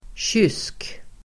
Ladda ner uttalet
Uttal: [tjys:k]